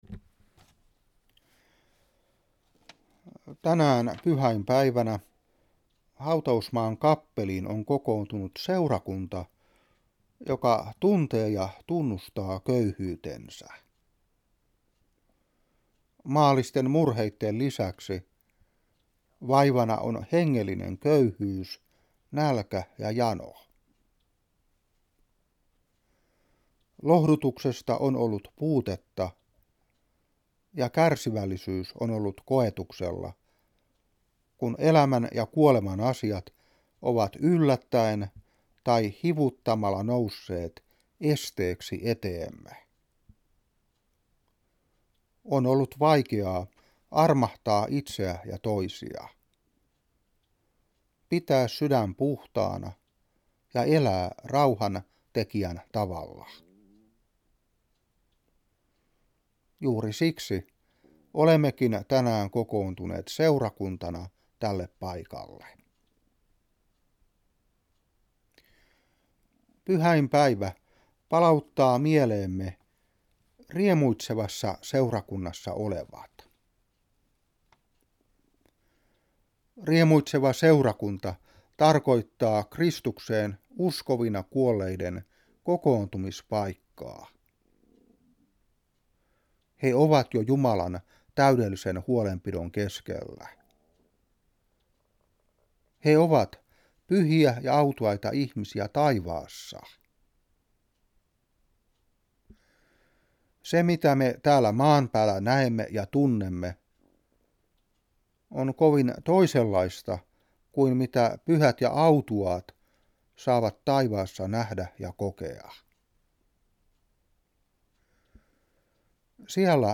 Saarna 2008-11.